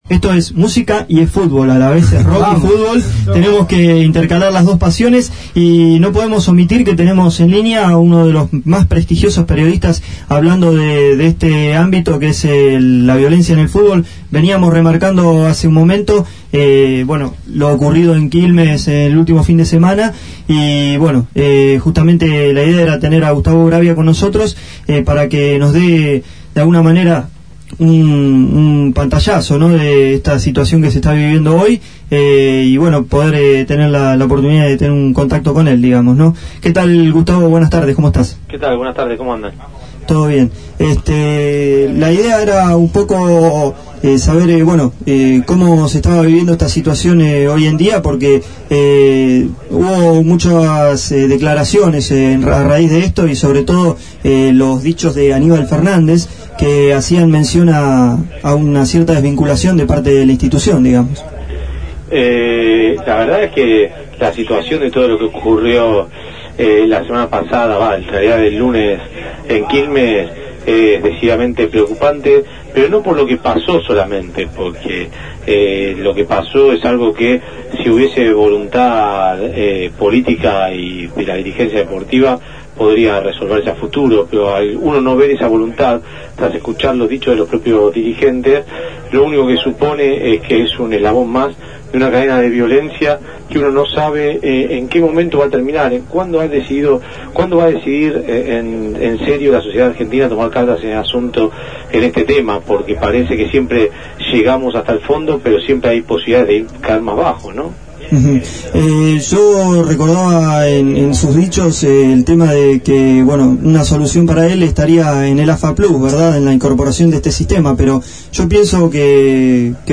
fue entrevistado en Jugala por la Banda